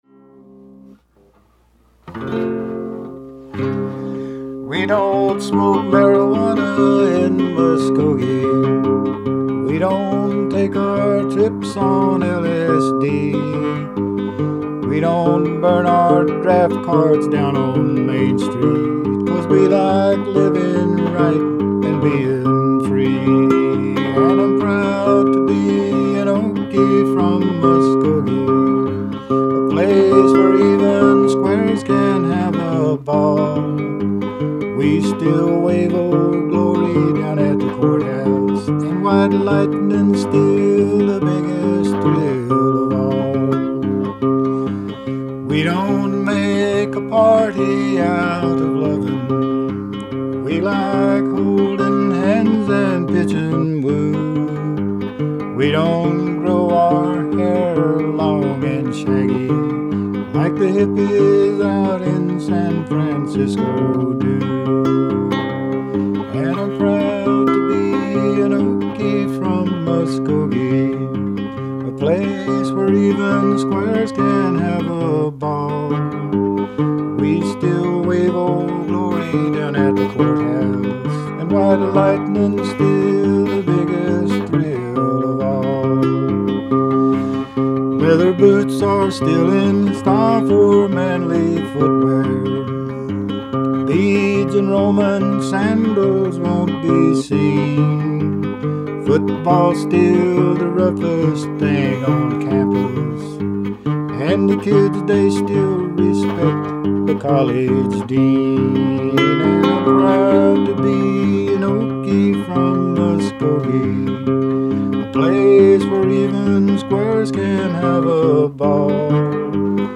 singing country songs, and playing his guitar.